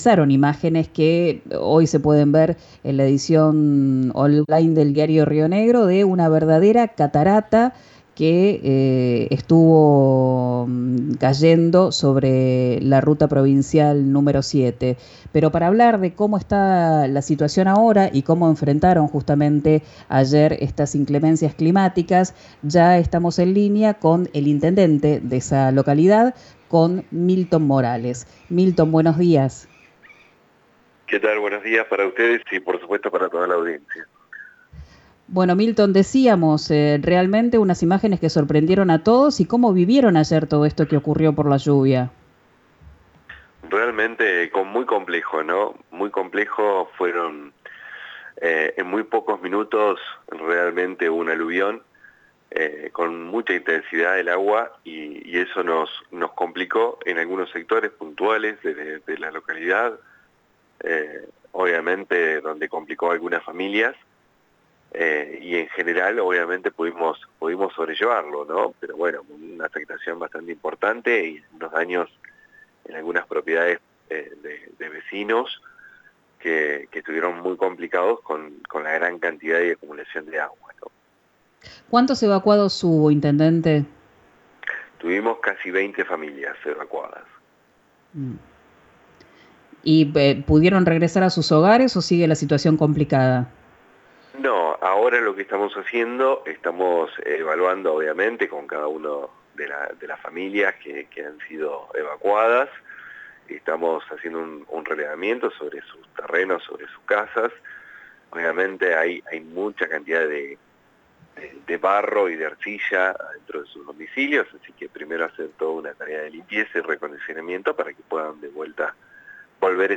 El intendente de la localidad Milton Morales dialogó en Quién dijo Verano, el programa de RÍO NEGRO RADIO, sobre las consecuencias de la tormenta.
Escuchá a Milton Morales, el intendente de Añelo, en «Quien dijo verano» por RÍO NEGRO RADIO: